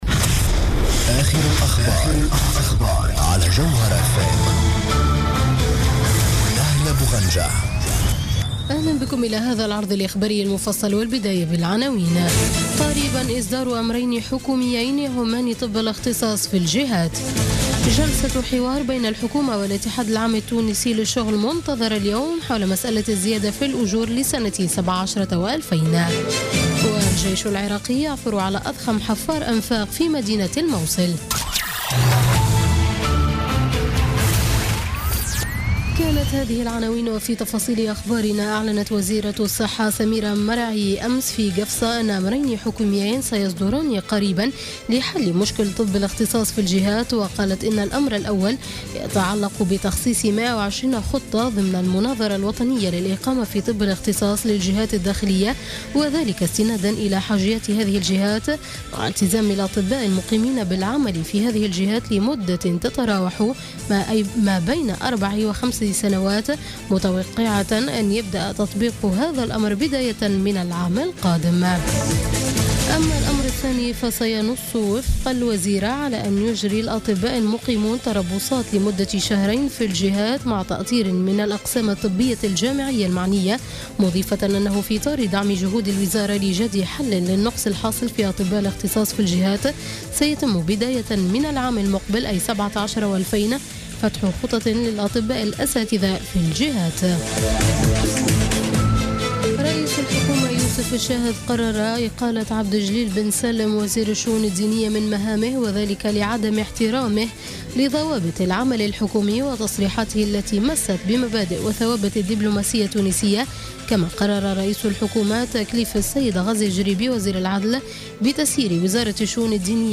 نشرة أخبار منتصف الليل ليوم السبت 5 نوفمبر 2016